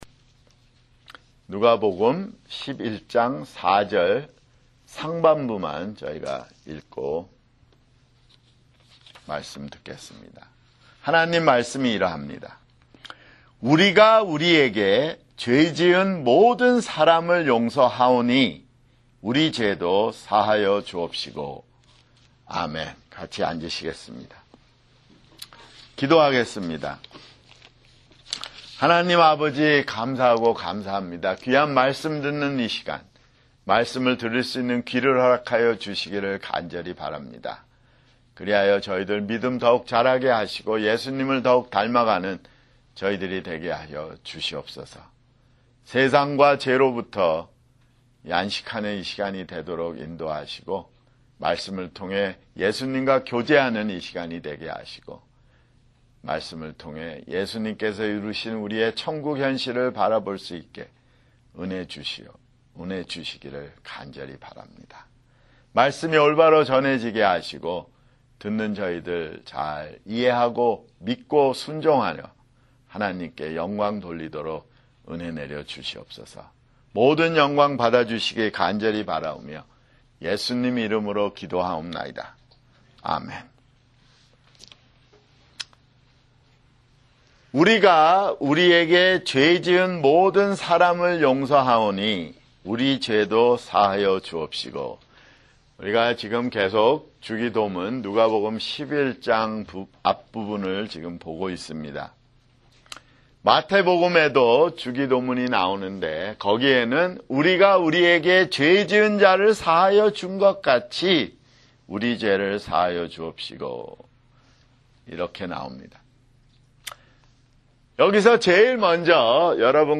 [주일설교] 누가복음 (78)